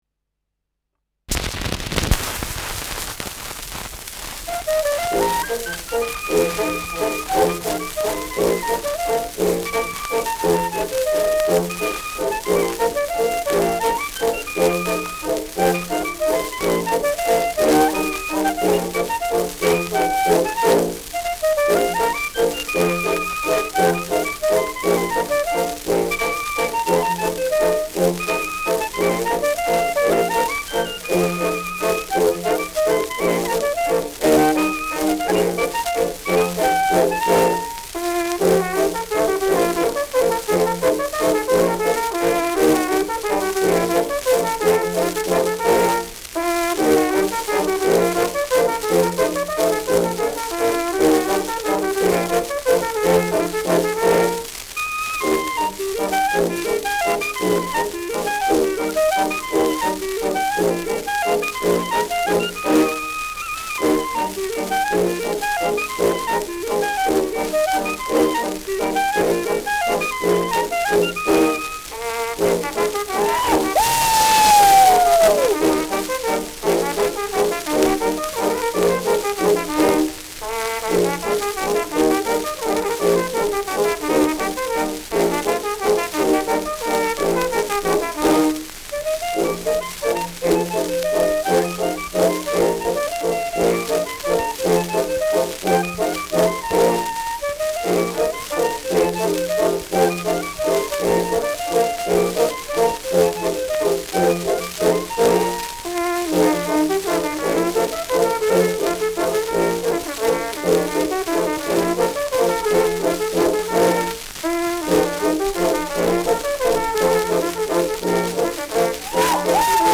Schellackplatte
ausgeprägtes Rauschen
Stadtkapelle Weißenburg (Interpretation)